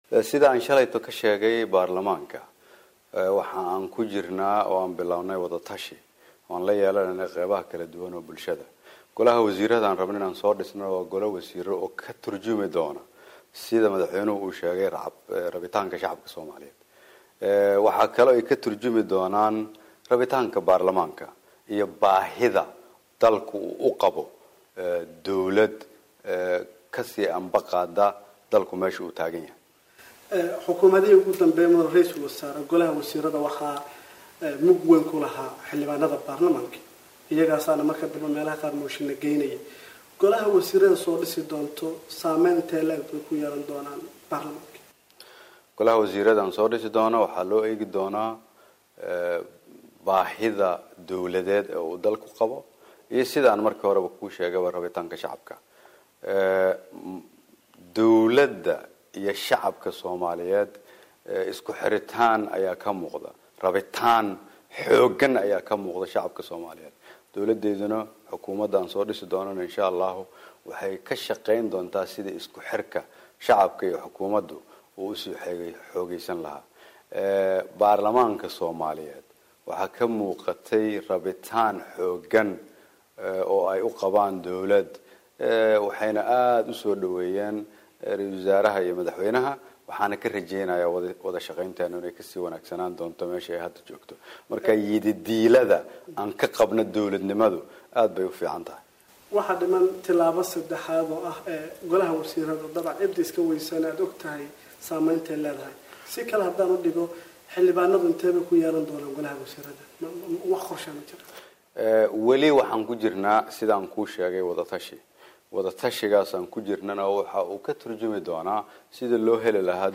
Wareysi: Ra'iisul wasaare Xasan